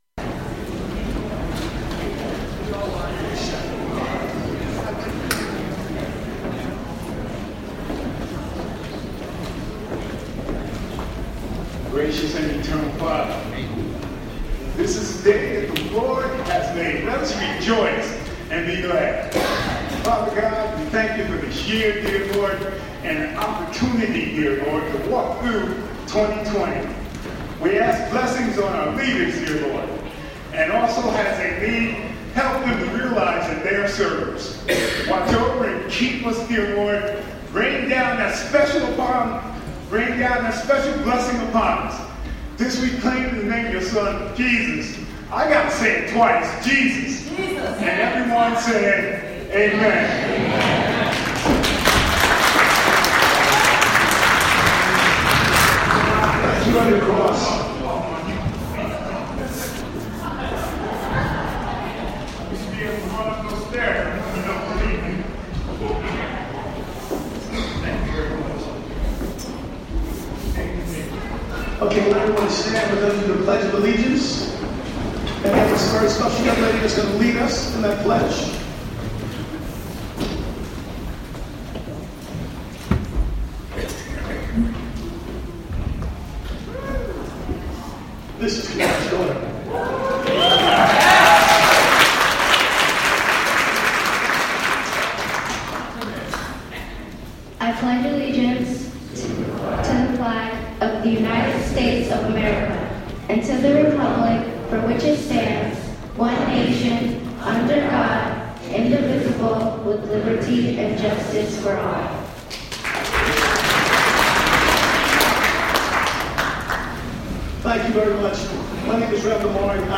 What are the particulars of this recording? City of Hudson Swearing in Ceremony at Hudson Hall on Warren St. Play In New Tab (audio/mpeg) Download (audio/mpeg)